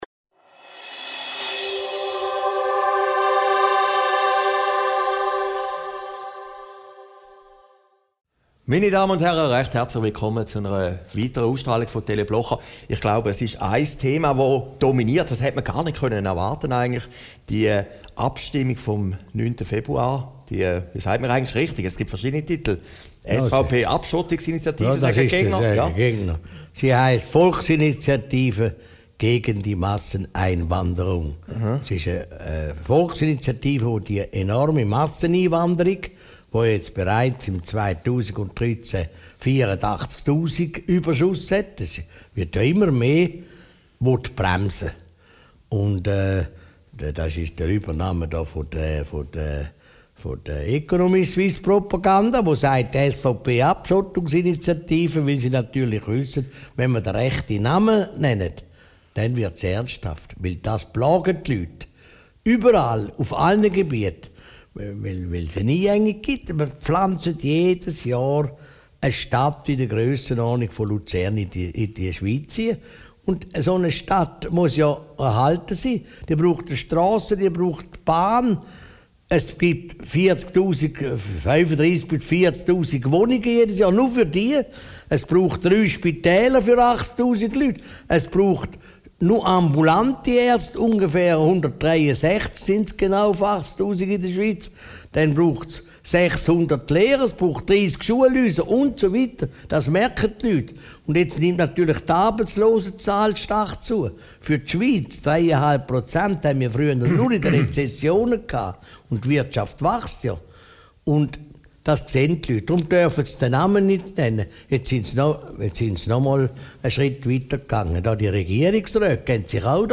Video downloaden MP3 downloaden Christoph Blocher über die Abstimmungsschlacht um die Masseneinwanderung, die Tricks der Economiesuisse und die Blocher-Puppe Aufgezeichnet in Herrliberg, 24. Januar 2014